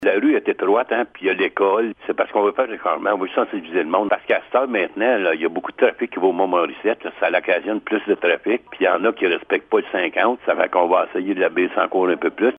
Le maire de Blue Sea, Laurent Fortin donne plus de précisions sur la raison de ce changement :